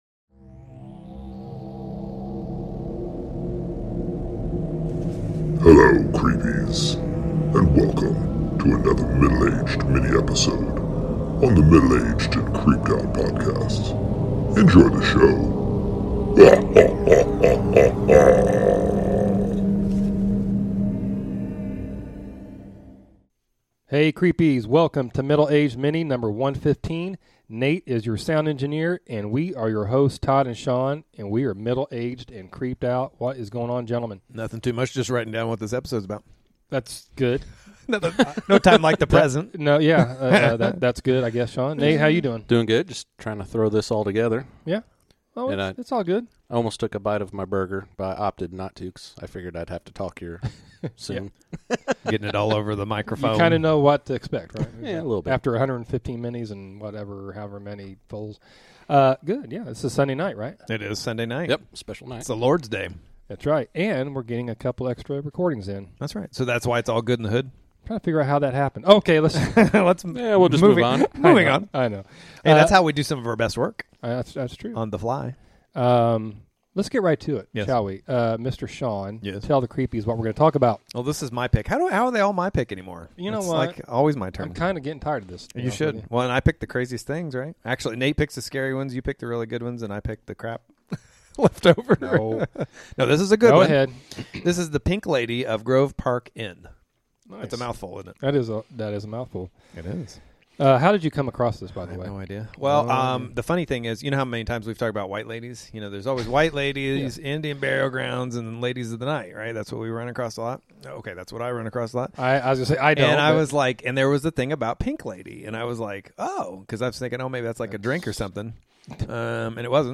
The guys have a discussion on the chilling and haunted history behind...The Pink Lady of Grove Park Inn!!!